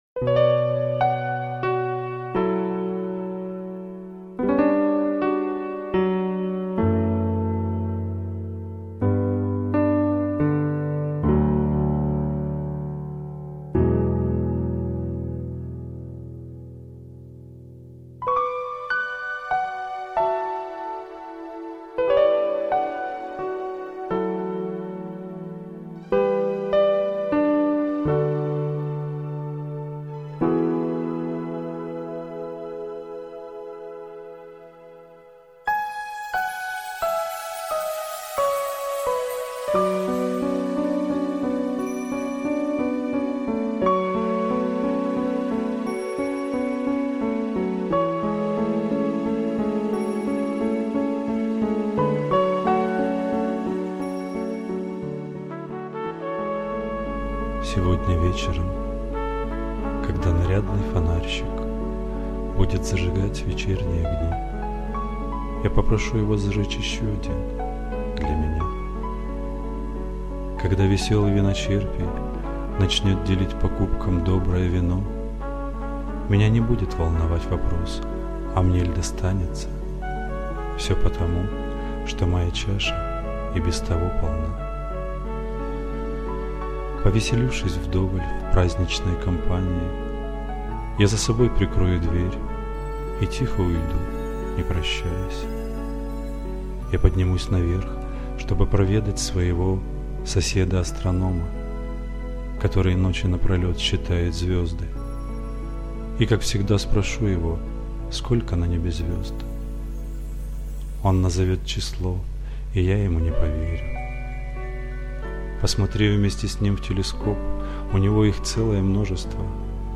Авторская мелодекламация
Музыка: Kamal – As Time Goes By